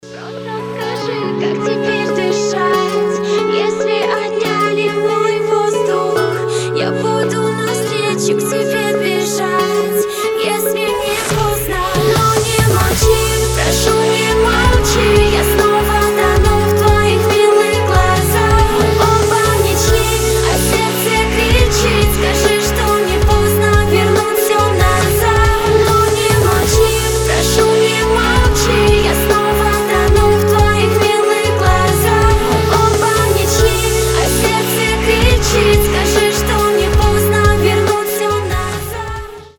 поп
лирика
Хип-хоп
красивый женский голос